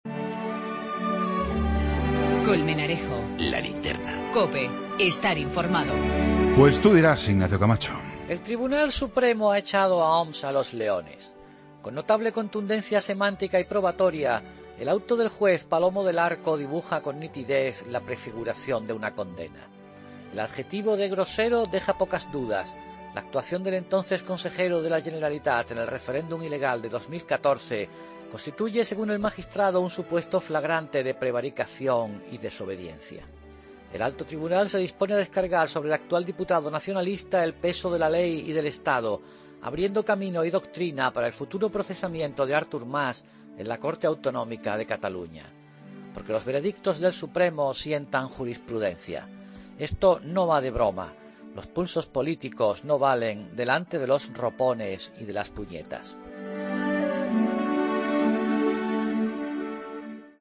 Redacción digital Madrid - Publicado el 29 dic 2016, 21:59 - Actualizado 17 mar 2023, 01:57 1 min lectura Descargar Facebook Twitter Whatsapp Telegram Enviar por email Copiar enlace El comentario de Ignacio Camacho en 'La Linterna'